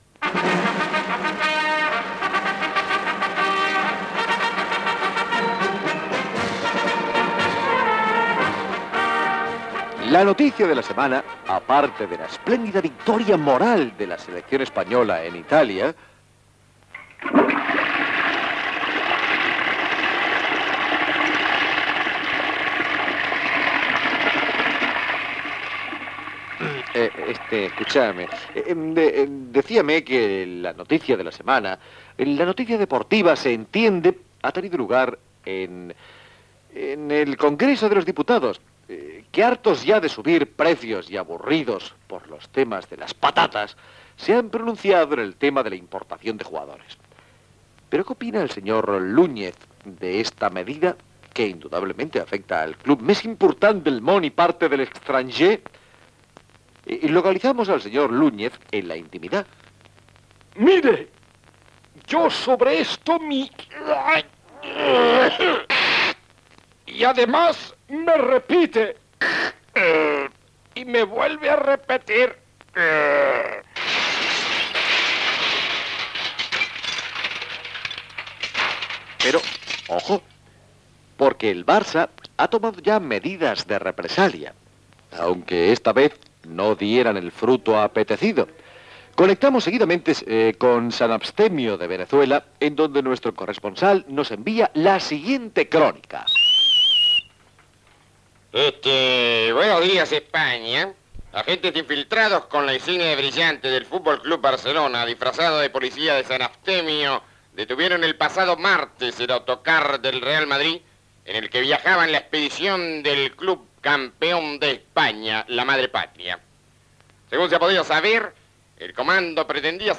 Notícies esportives de la setmana
comiat i sintonia de sortida